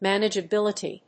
音節man・age・a・bil・i・ty 発音記号読み方/m`ænɪdʒəbíləṭi/ 名詞